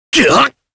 Kain-Vox_Damage_jp_01.wav